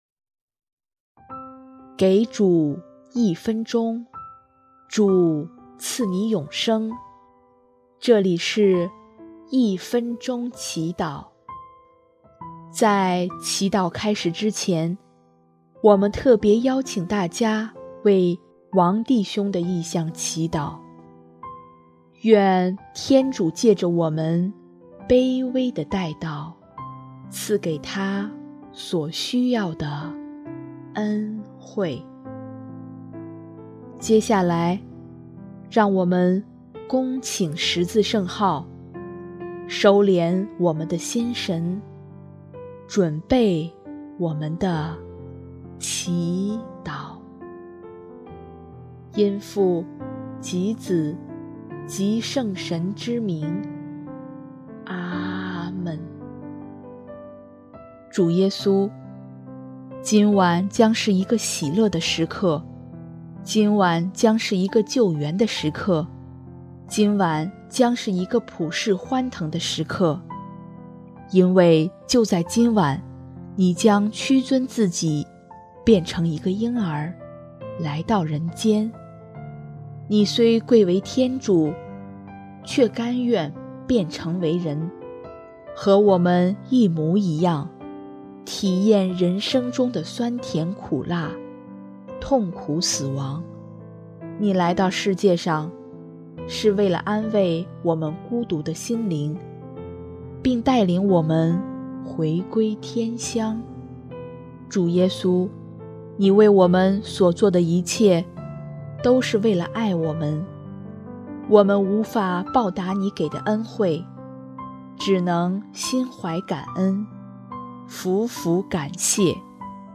【一分钟祈祷】|12月24日 喜迎耶稣圣诞，感谢天恩！